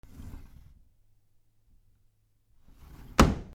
ふすま 強く
/ K｜フォーリー(開閉) / K05 ｜ドア(扉)